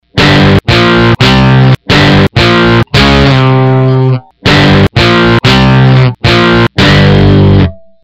残業して徹夜したため、寝ぼけてギターなんて衝動買いしてみたのだ。
練習1日目2日目でネットにアップするという暴挙に出てみる。
ちなみに2日目は単音を録音して編集してみた。